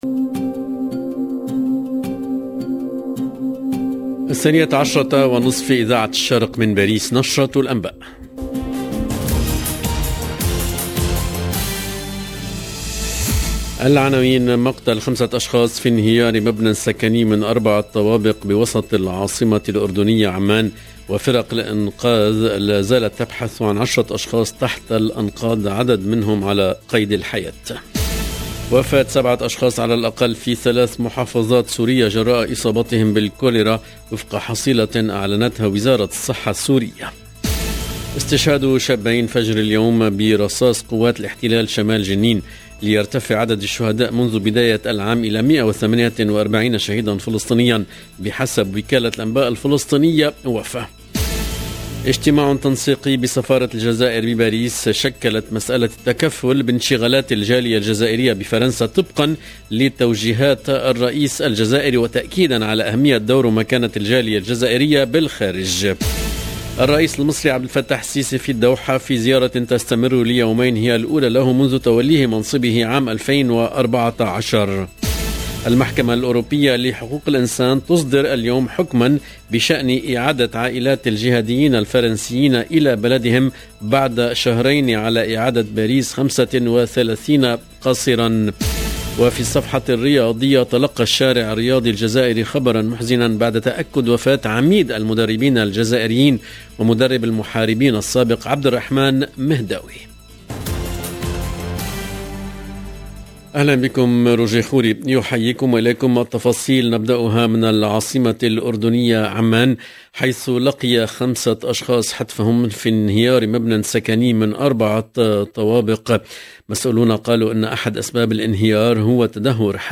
LE JOURNAL EN LANGUE ARABE DE MIDI 30 DU 14/09/22